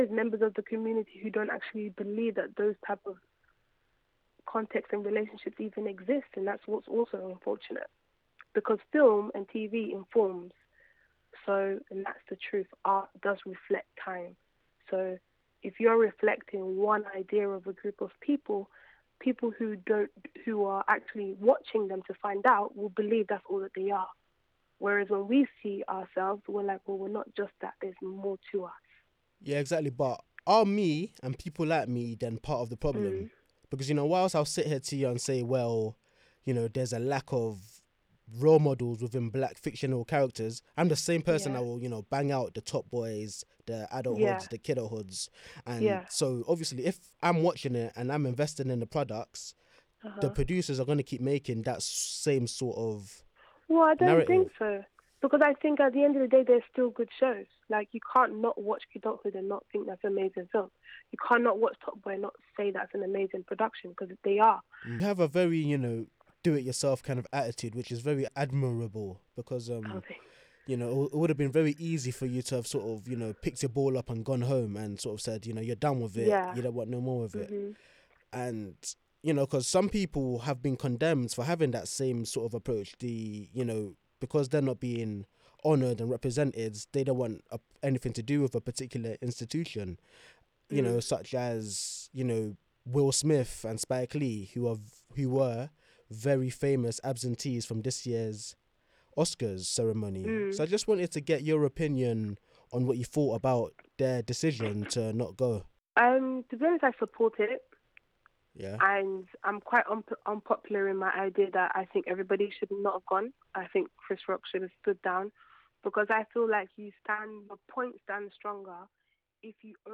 This  2 minute clip was taken from an interview